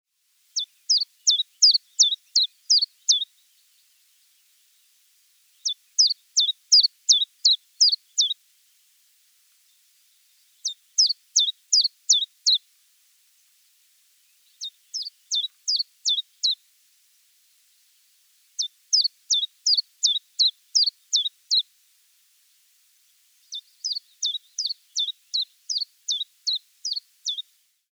Stimme
Wie der Stieglitz und der Kuckuck, so ruft auch der Zilpzalp seinen eigenen Namen: „Zilp zalp zalp zilp zalp zilp zilp zalp“ tönt es lauthals aus so manchem Gebüsch.
1678_Zilpzalp_Gesang.mp3